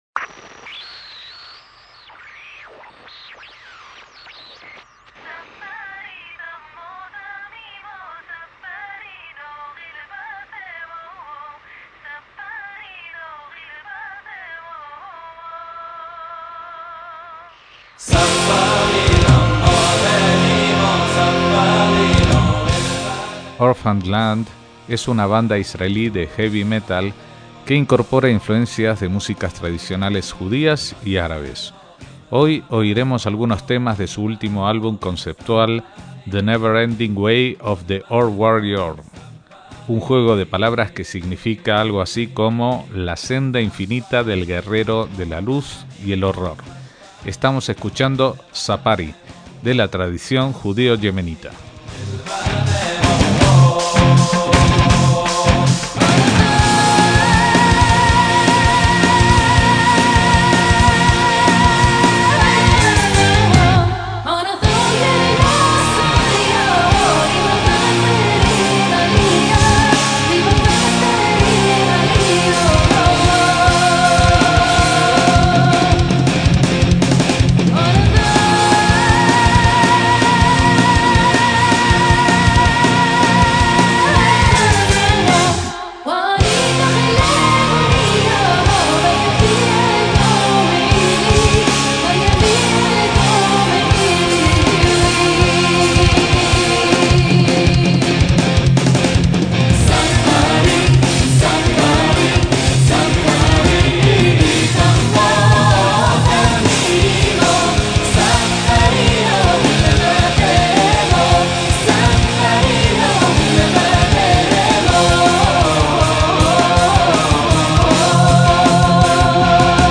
rock heavy metal
guitarras
bajo